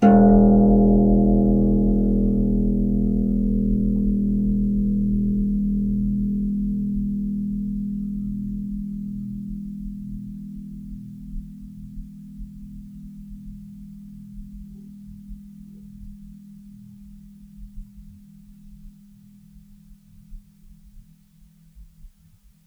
KSHarp_E1_f.wav